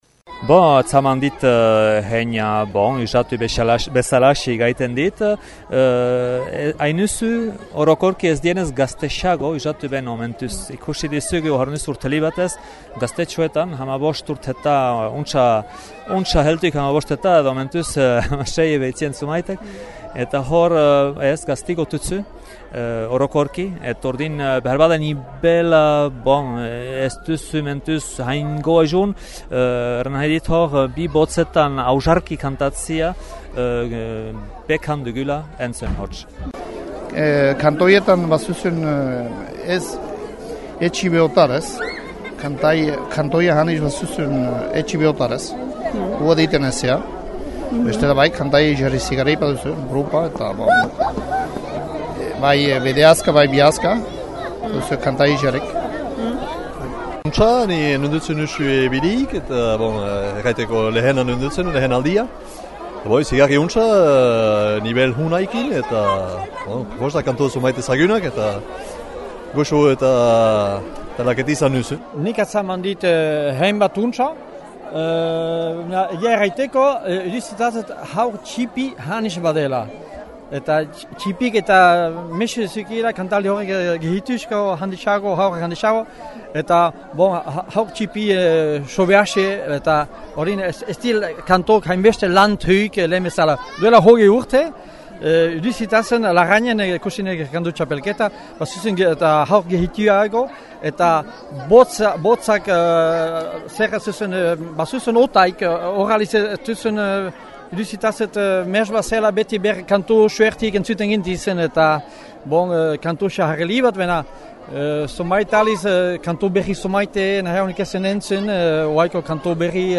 Entzün püblikoak zer zeion Kantu Txapelketa hortaz :